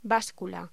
Locución: Báscula